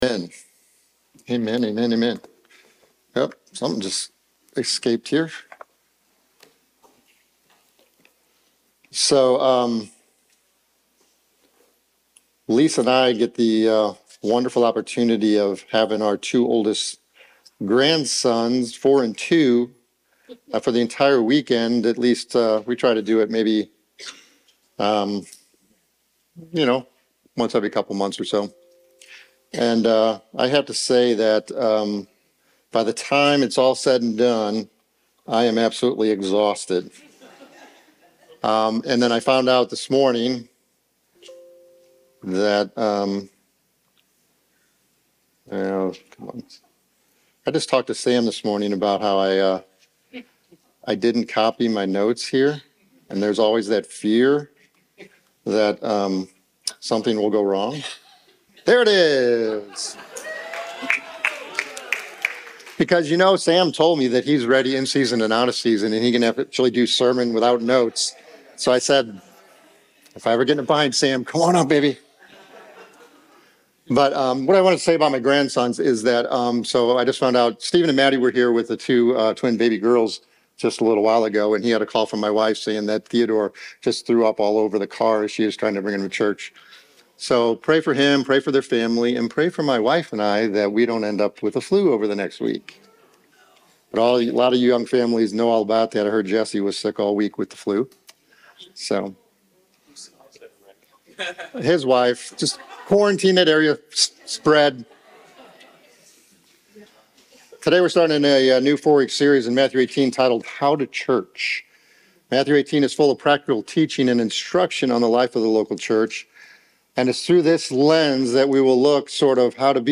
Throughout the sermon, he emphasized the need for humility, reminding us that greatness in God's kingdom comes from a childlike faith and dependence on God rather than pride or self-sufficiency. He encouraged everyone to engage in genuine community life by serving and supporting one another, thus reflecting Christ's love and fostering a welcoming environment in the church.